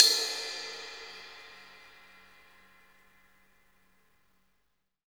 CYM LTN C0LR.wav